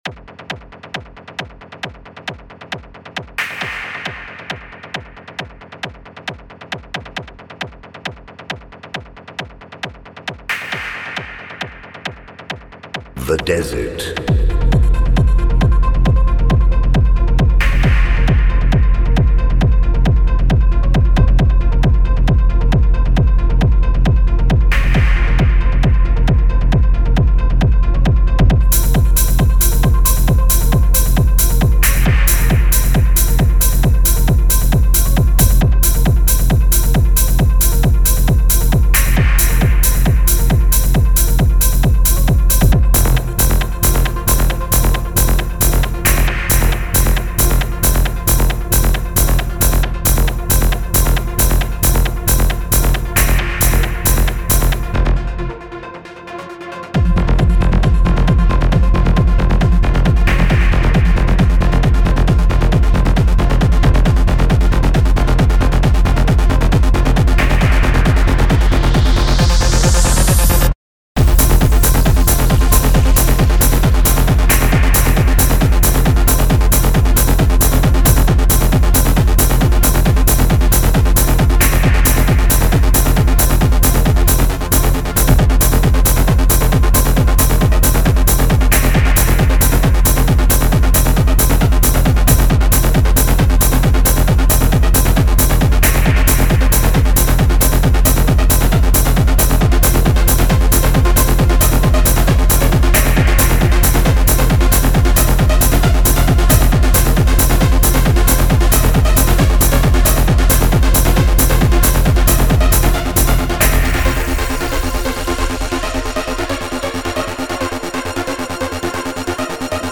Trance remix